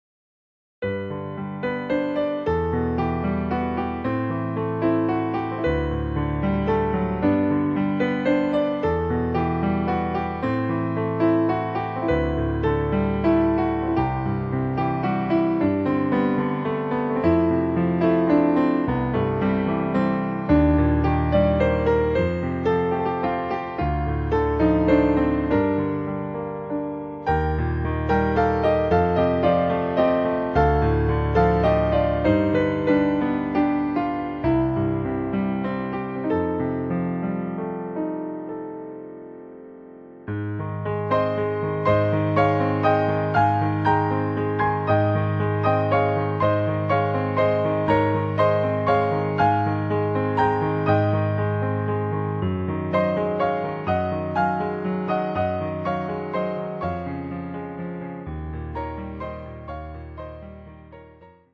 〜 透明感あふれる3rdピアノソロアルバム。
3rdピアノソロアルバム。